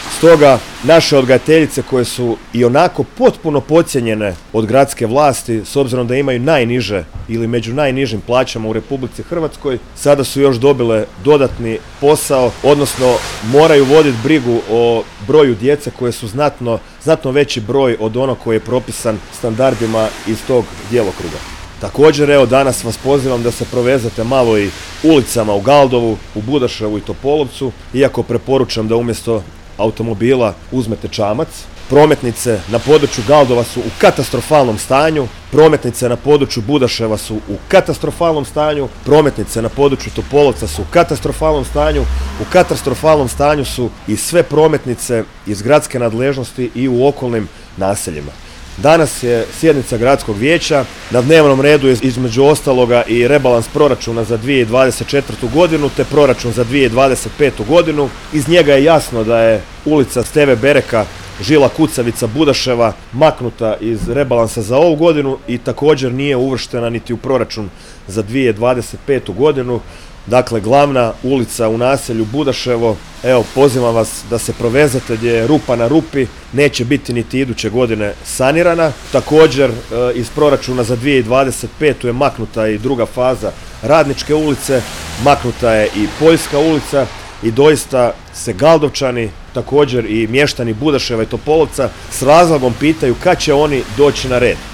Gradska organizacija HDZ-a Sisak održala je u petak, 22. studenog 2024. godine, tiskovnu konferenciju ispred odgojno-obrazovnog kompleksa u Galdovačkoj ulici, na temu: „Poziv na otvorenje vrtića u Galdovu”.